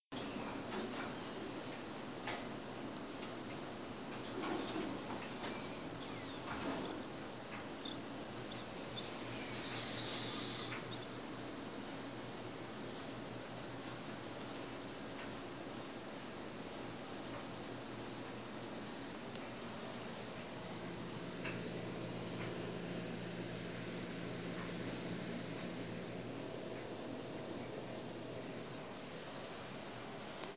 Field Recording Número Cinco
Location: Outside of Nassau dormitory building Laundry Room
Sounds Featured: Hum of electronic Dryers, Nose Squeaking, Elevator doors opening